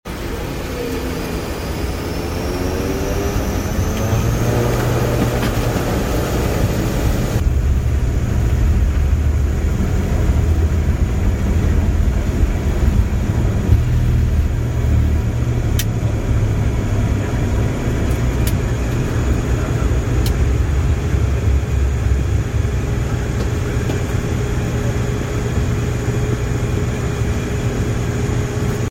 How to take off an ATR 72! sound effects free download